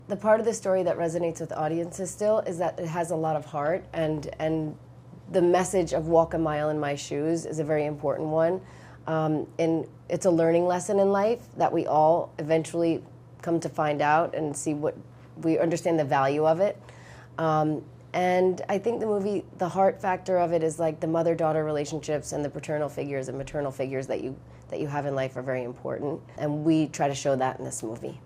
Repreising her Freaky Friday role over twenty years later was not a difficult task for Lindsay Lohan, and she explained why it was an easy transition during a recent interview.